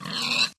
Sound / Minecraft / mob / pig / death.ogg